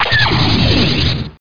TIE-Fire